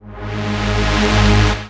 VEC3 FX Athmosphere 24.wav